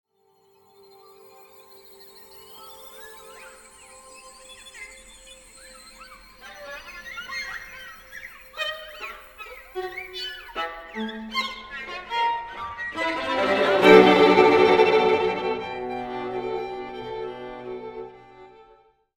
for string quartet